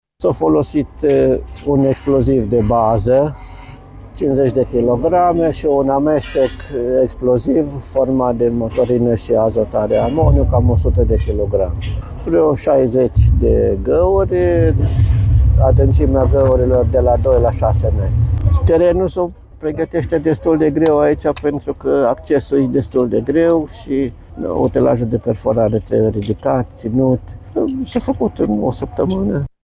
inginer miner